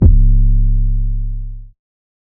guidance C#.wav